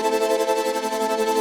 Index of /musicradar/shimmer-and-sparkle-samples/170bpm
SaS_MovingPad01_170-A.wav